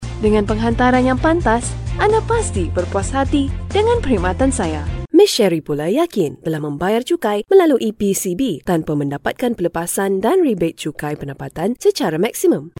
马来西亚语翻译团队成员主要由中国籍和马来籍的中马母语译员组成，可以提供证件类翻译（例如，驾照翻译、出生证翻译、房产证翻译，学位证翻译，毕业证翻译、成绩单翻译、无犯罪记录翻译、营业执照翻译、结婚证翻译、离婚证翻译、户口本翻译、奖状翻译等）、公证书翻译、病历翻译、马来语视频翻译（听译）、马来语语音文件翻译（听译）、技术文件翻译、工程文件翻译、合同翻译、审计报告翻译等；马来西亚语配音团队由马来西亚籍的马来语母语配音员组成，可以提供马来语专题配音、马来语广告配音、马来语教材配音、马来语电子读物配音、马来语产品资料配音、马来语宣传片配音、马来语彩铃配音等。
马来西亚语样音试听下载